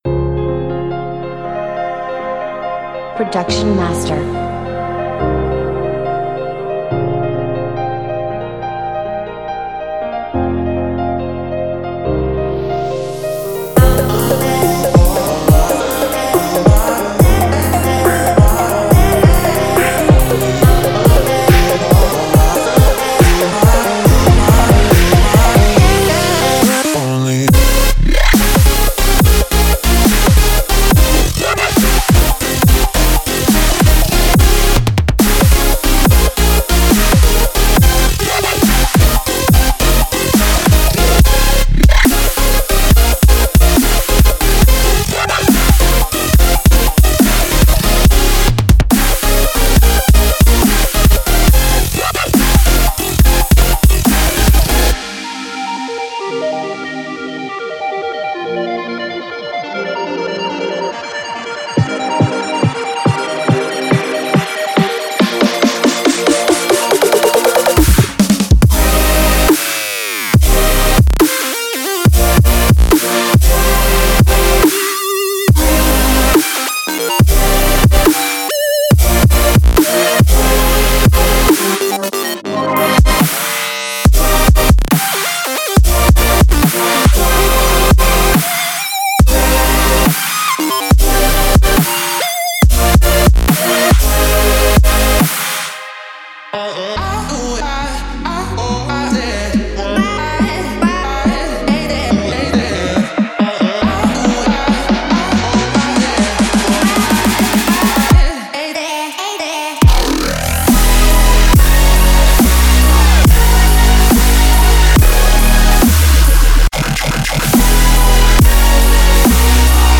professional melodic dubstep samples
24 bit WAV Stereo